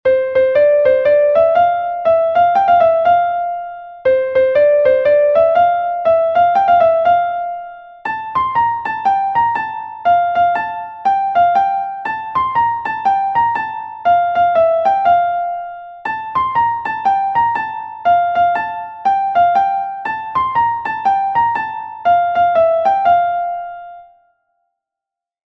Gavotte Diaouled Ar Menez II is a Gavotte from Brittany recorded 1 times by Diaouled Ar Menez